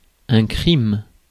Ääntäminen
France: IPA: [kʁim]